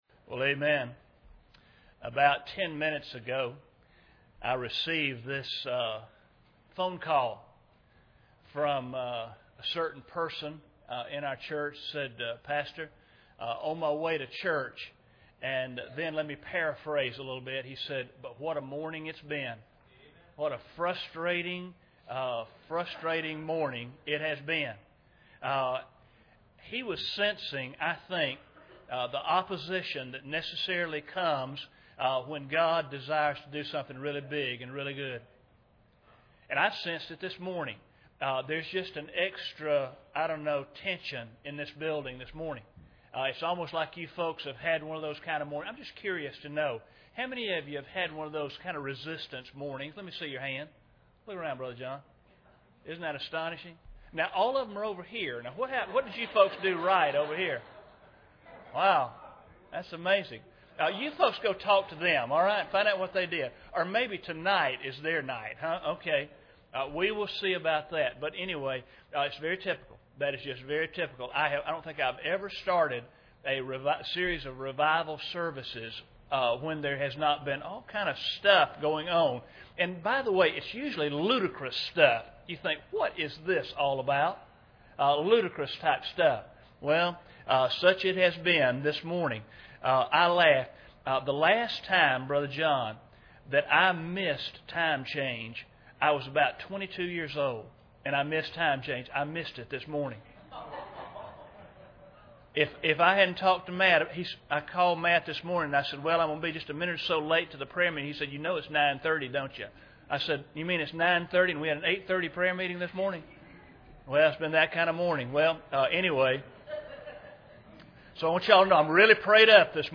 Series: 2011 Awakening Conference
Service Type: Sunday Morning